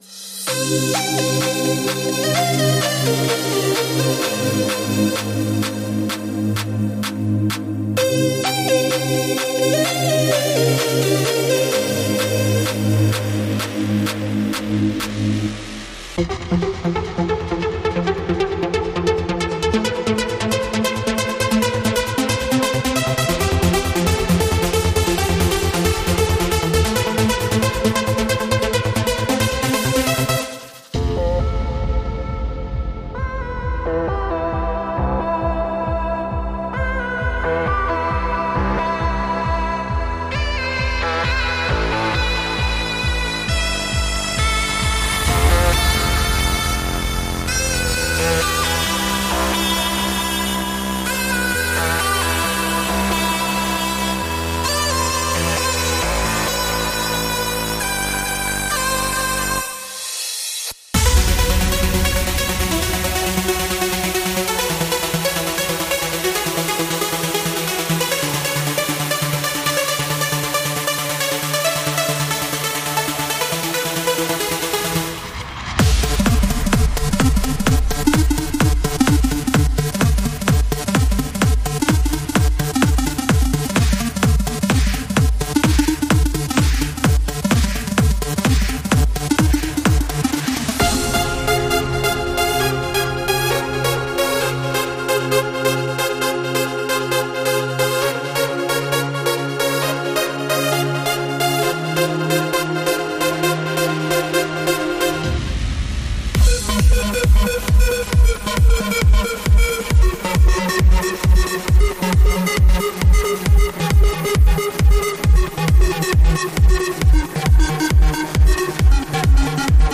Progressive Trance House Melodic Cinematic